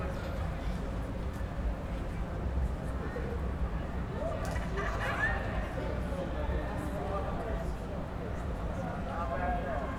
Environmental
UrbanSounds
Streetsounds
Noisepollution